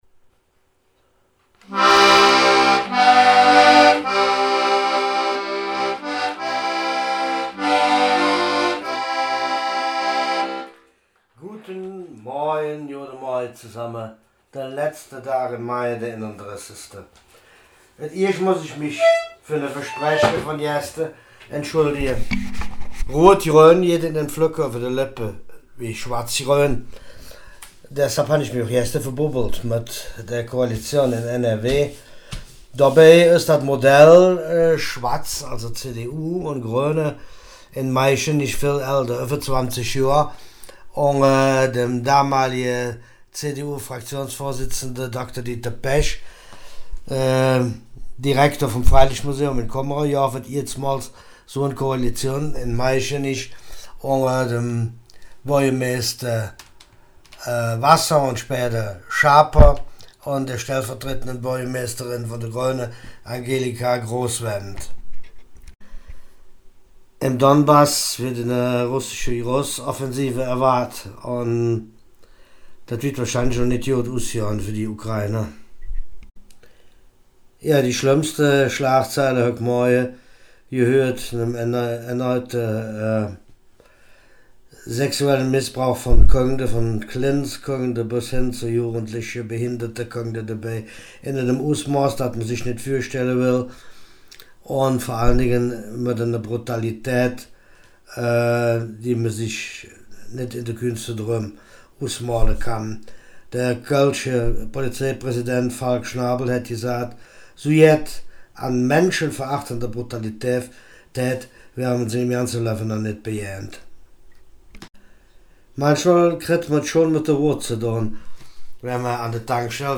Nachrichten vom 31. Mai